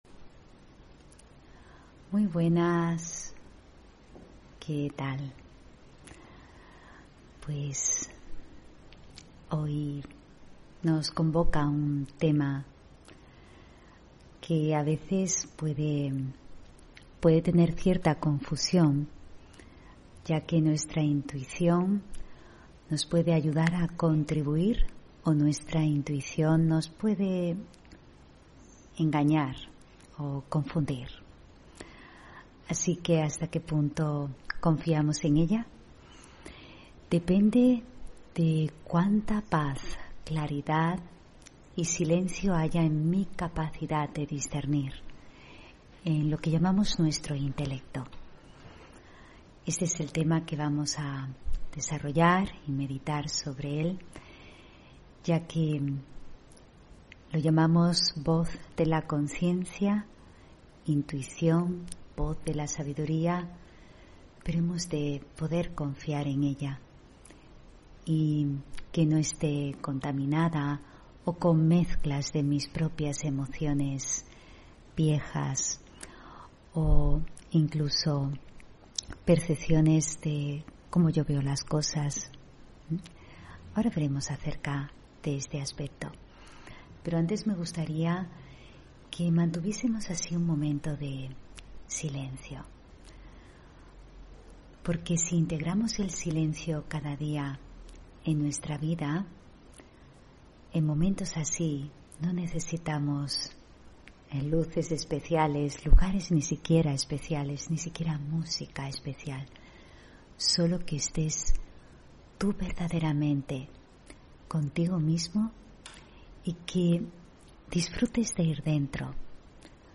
Meditación y conferencia: ¿Confío en mi intuición?